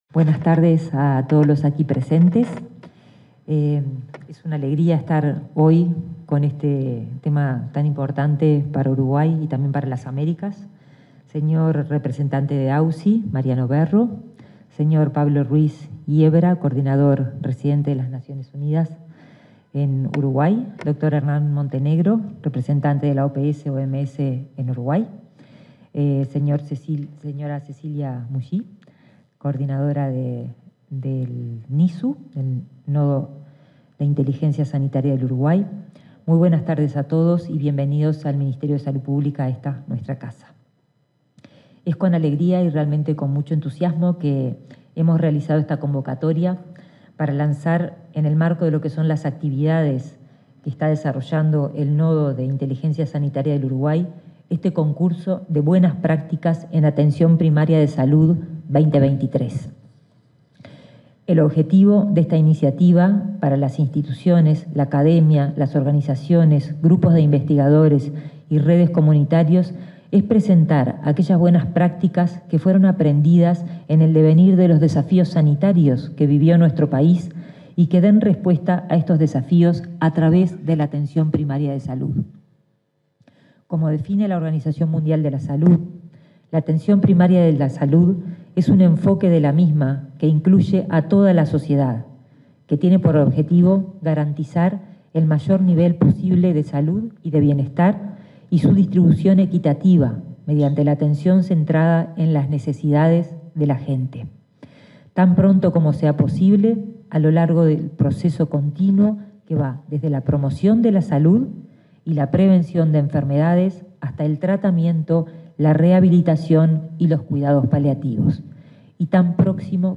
Lanzamiento del concurso de buenas prácticas en atención primaria de salud 02/08/2023 Compartir Facebook X Copiar enlace WhatsApp LinkedIn En el marco de la ceremonia de lanzamiento del concurso de buenas prácticas en atención primaria de salud, se expresaron la ministra de Salud Pública, Karina Rando y el director ejecutivo de la Agencia Uruguaya de Cooperación Internacional, Mariano Berro.